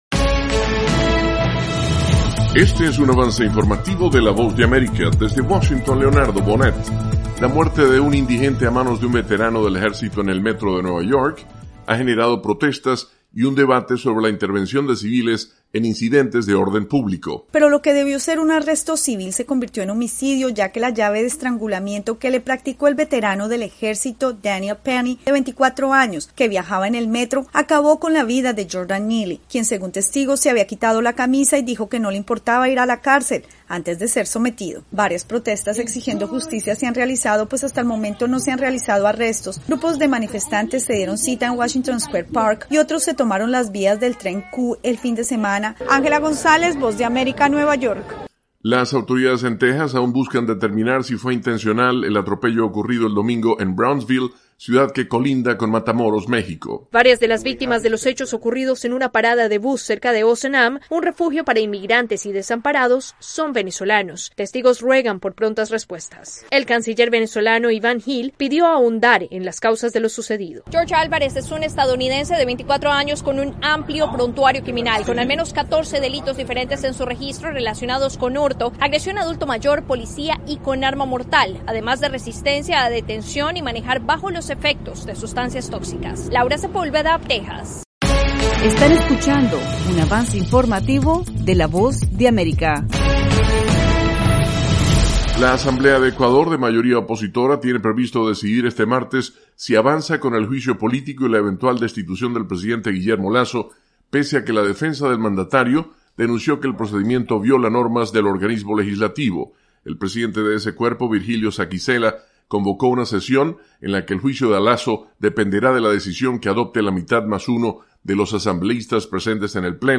El siguiente es un avance informativo presentado por la Voz de América, desde Washington, con